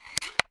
Drag&Drop-Drop.mp3